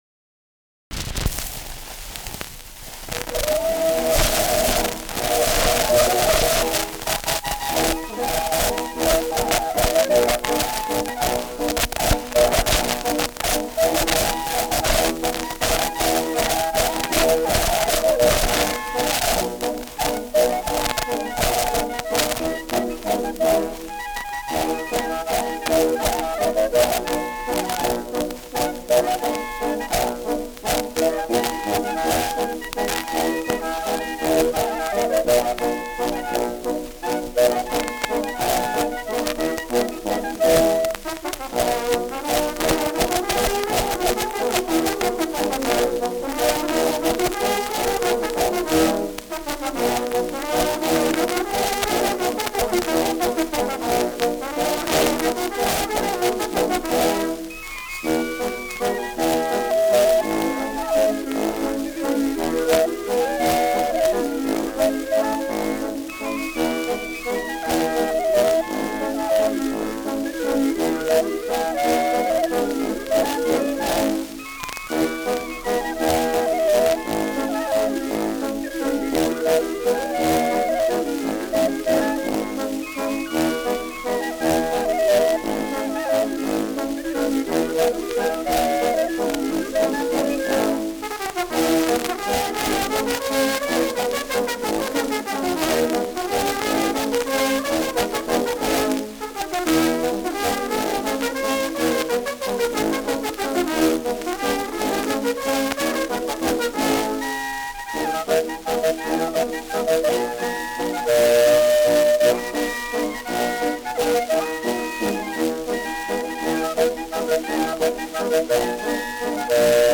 Schellackplatte
Stark verrauscht : Gelegentlich stärkeres Knacken